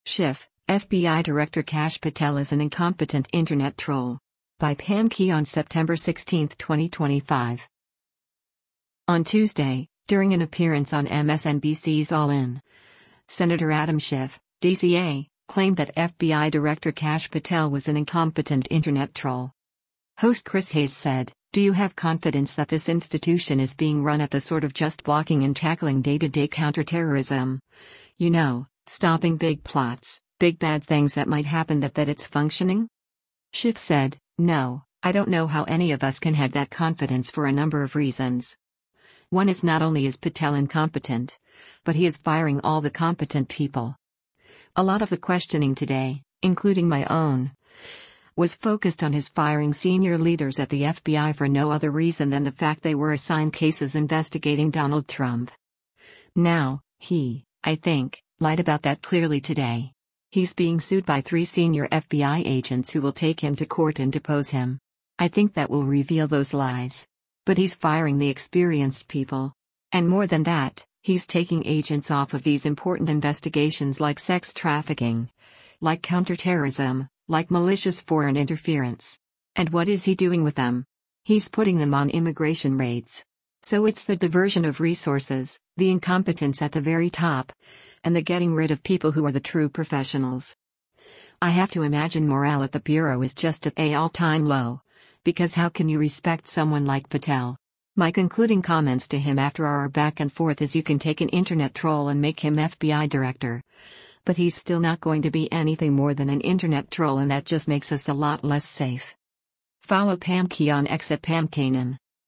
On Tuesday, during an appearance on MSNBC’s “All In,” Sen. Adam Schiff (D-CA) claimed that FBI Director Kash Patel was an “incompetent” internet troll.